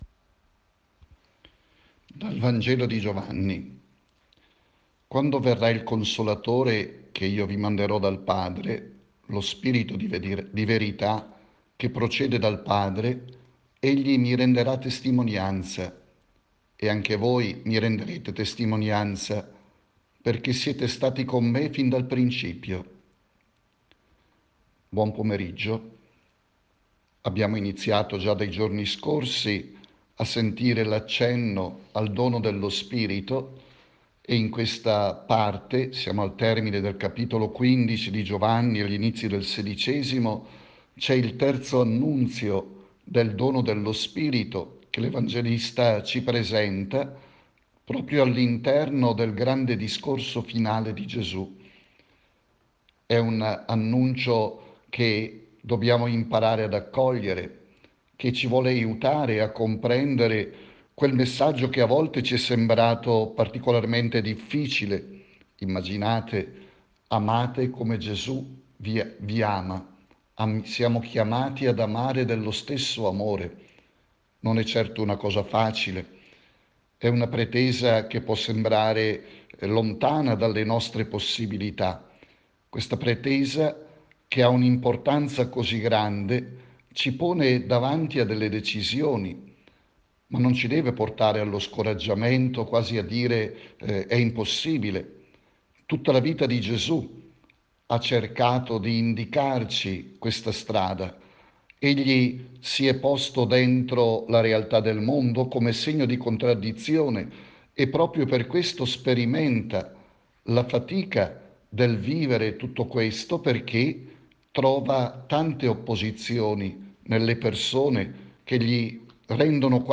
riflessione